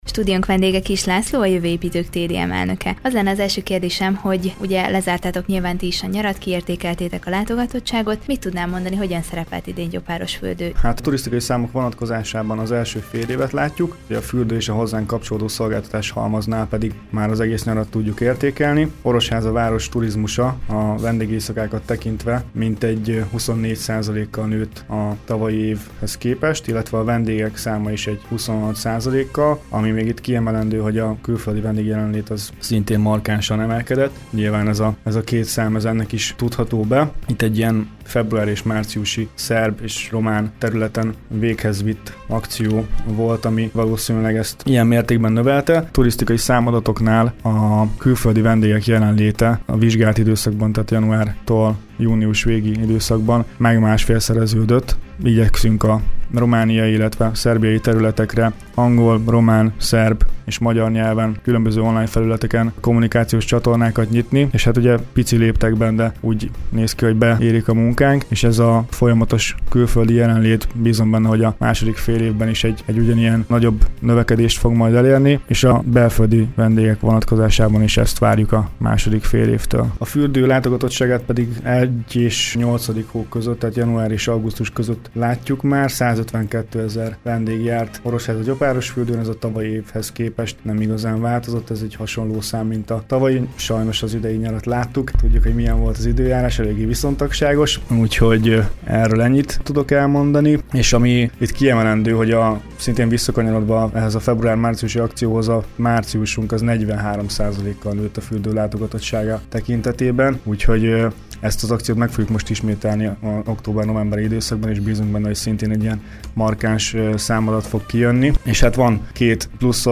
Vele beszélgetett tudósítónk az idei nyár értékeléséről, valamint az új Dottó vonat sikeréről.